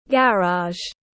Garage /ˈɡær.ɑːʒ/